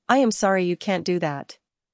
Play, download and share siri – sorry you cant do that original sound button!!!!
siri-sorry-you-cant-do-that.mp3